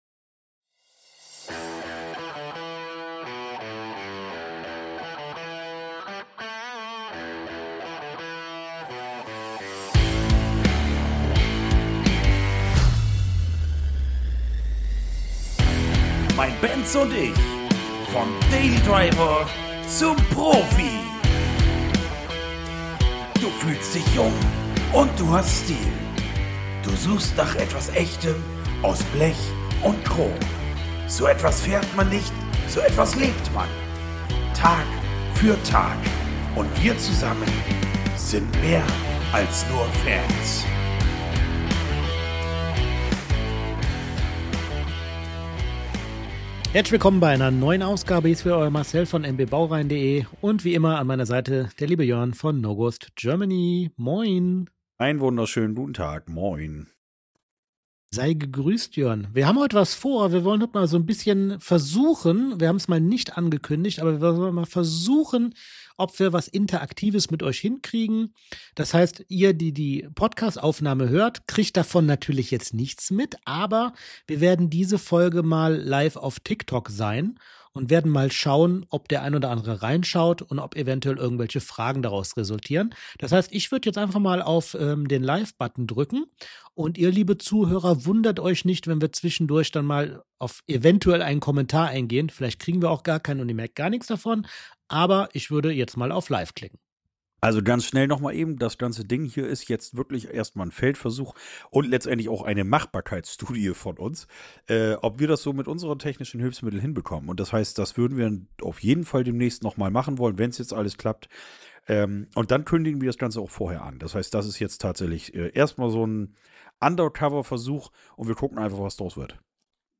#30 - Vom Daily Driver zum Profi (LIVESENDUNG) ~ Mein Benz & ich Podcast